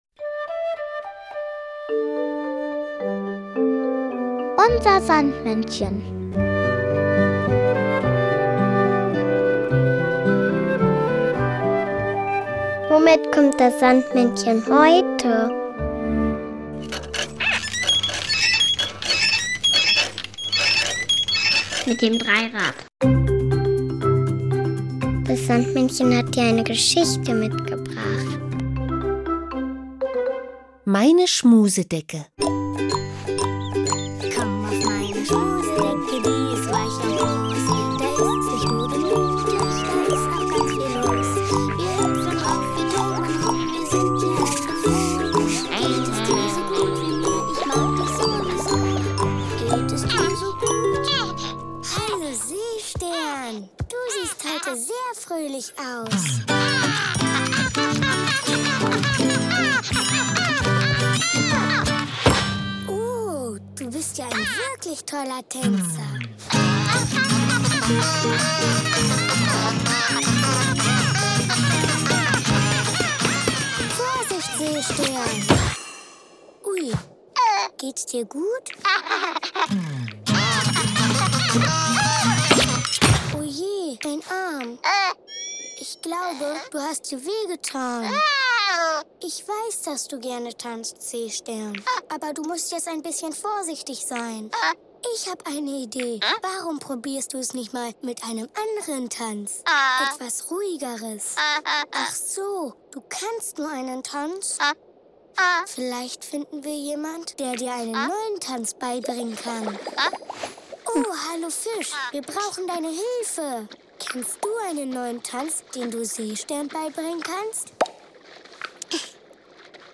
Kinderlied "Fischflossenflipflops" von Willy Astor.